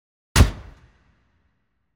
sfx_spotlight.ogg